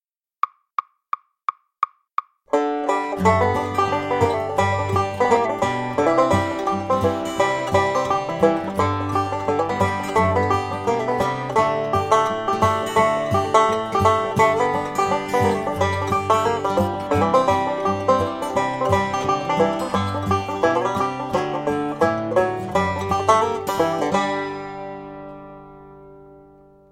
Banjo à 5 Cordes